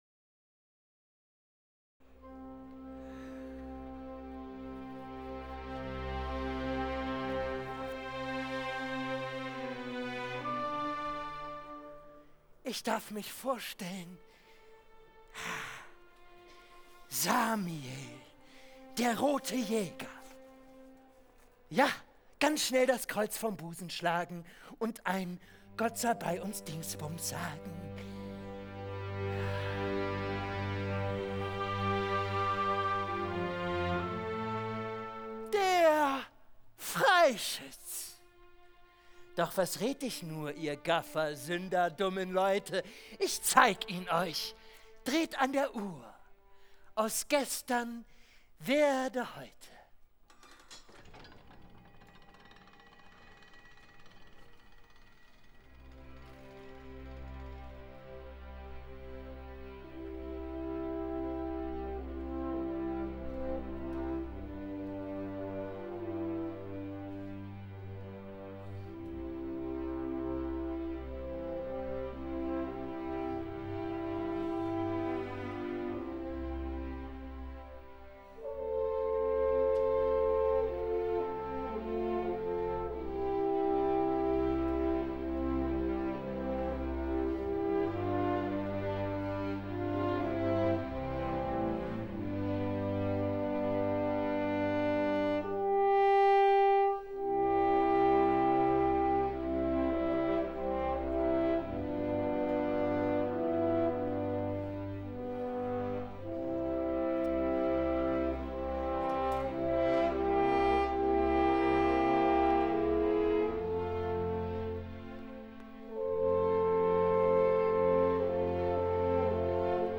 Overture aus "Der Freischütz" (Wiener Symphoniker)
overture.mp3